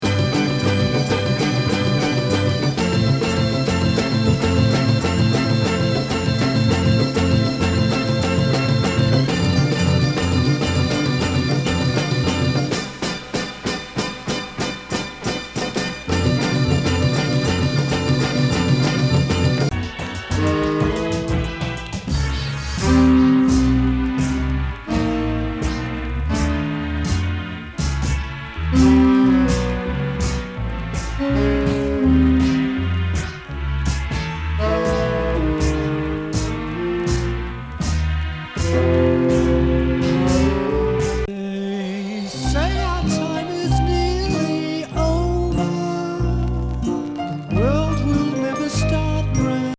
一派のカンタベリー・サウンドの成れの果て？ある種結晶なアヴァン過ぎポップ！
他垣間見えるヒネクレ・ポップ・センスとアヴァンの同居。